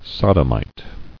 [sod·om·ite]